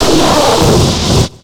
Cri de Maganon dans Pokémon X et Y.